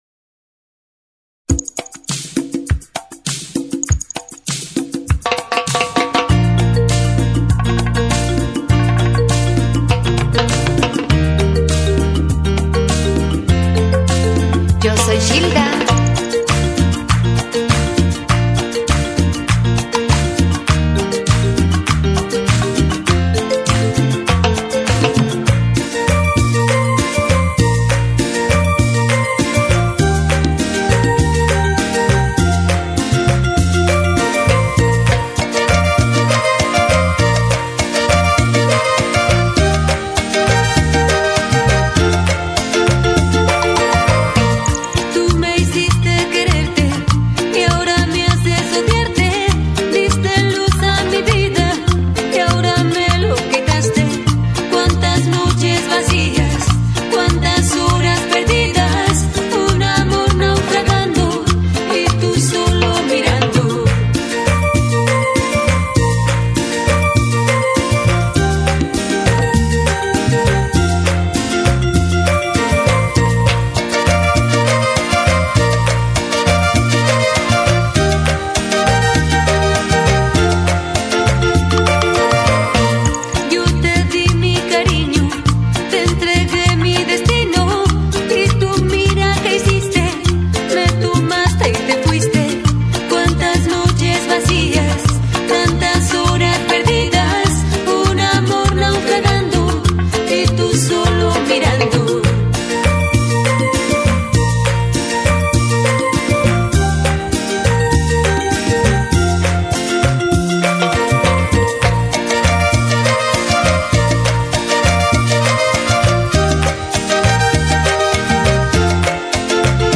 Carpeta: Cumbia y + mp3
en vivo